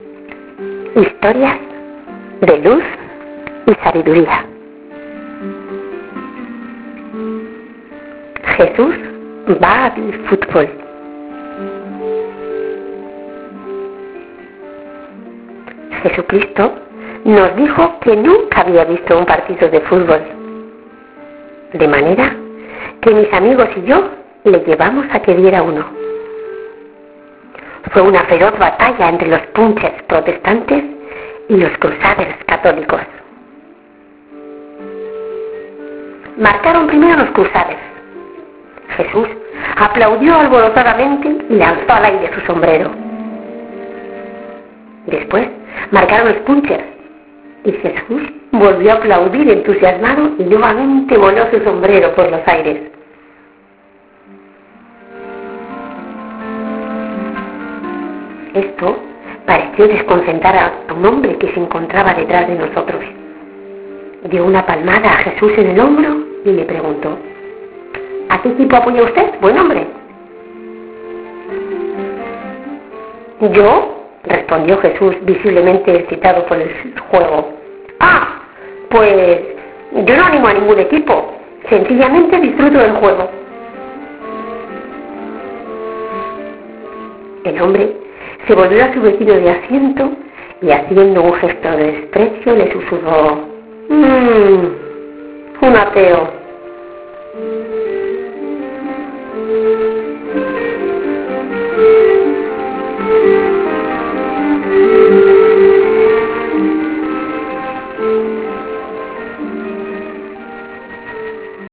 historia narrada